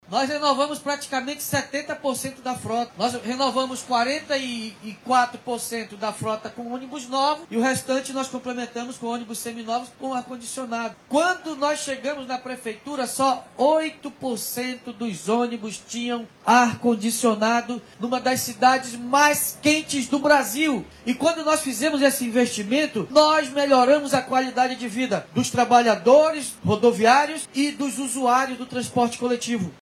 SONORA-2-PREFEITO.mp3